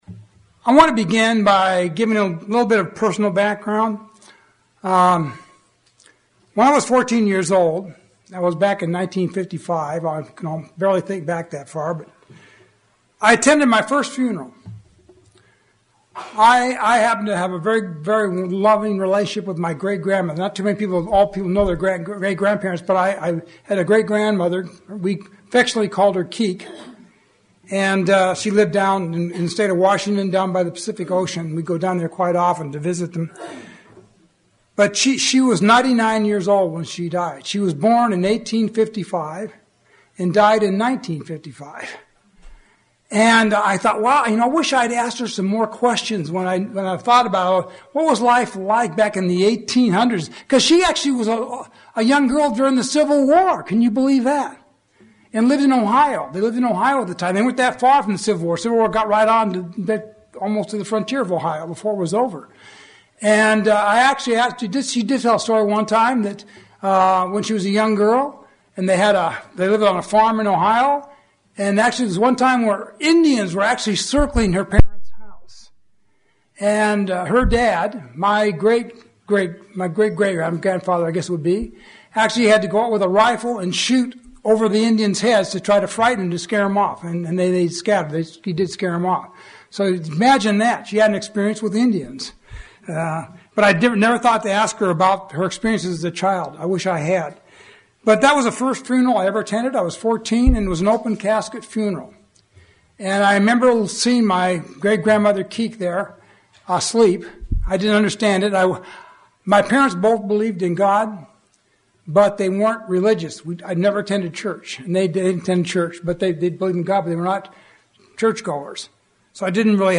Seven lessons that can help see us through any and all trials. sermon Transcript This transcript was generated by AI and may contain errors.